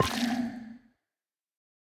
Minecraft Version Minecraft Version 1.21.5 Latest Release | Latest Snapshot 1.21.5 / assets / minecraft / sounds / block / sculk_shrieker / place5.ogg Compare With Compare With Latest Release | Latest Snapshot